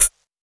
Sparkly Hat One Shot D# Key 05.wav
Royality free hat tuned to the D# note. Loudest frequency: 9832Hz
.WAV .MP3 .OGG 0:00 / 0:01 Type Wav Duration 0:01 Size 36,37 KB Samplerate 44100 Hz Bitdepth 16 Channels Mono Royality free hat tuned to the D# note.
sparkly-hat-one-shot-d-sharp-key-05-uRo.mp3